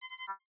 NOTIFICATION_Digital_05_mono.wav